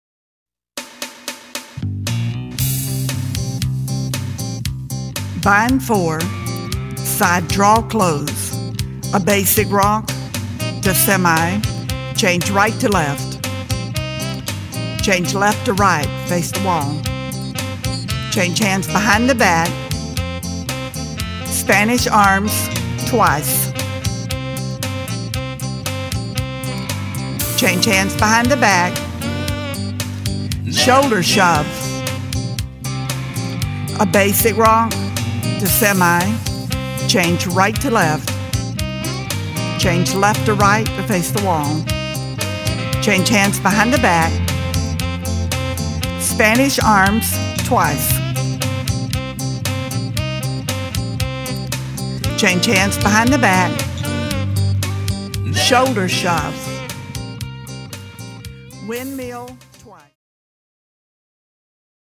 Jive